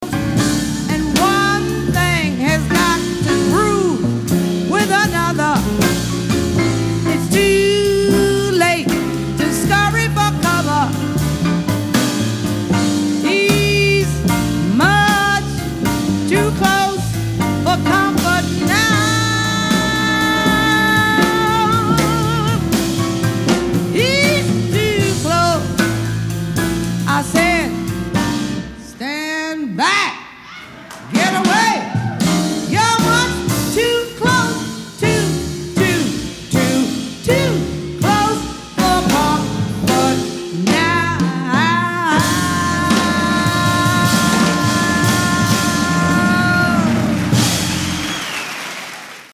bongos